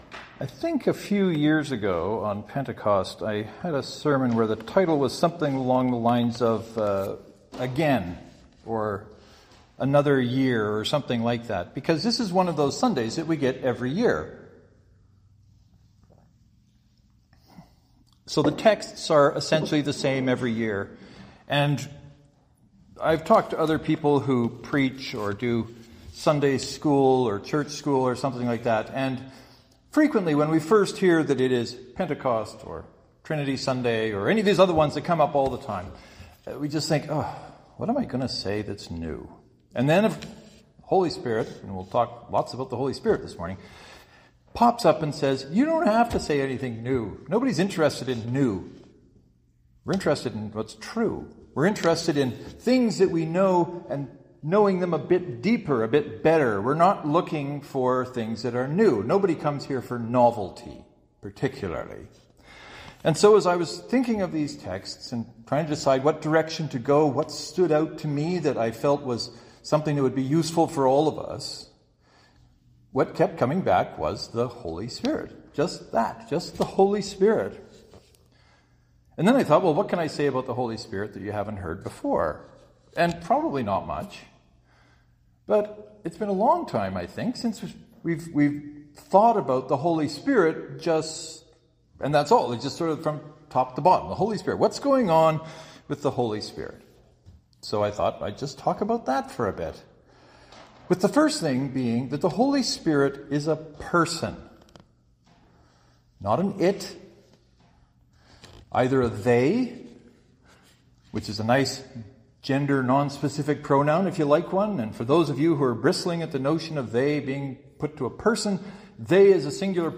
The sermons explore these things and a few others and look at their implications for us. I spend some time talking about pronouns for the Holy Spirit, namely “they,” and “she.” I spend more time talking about what it means to be in a relationship with God through Jesus empowered by (this) Holy Spirit.